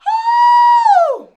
HUUUUUH.wav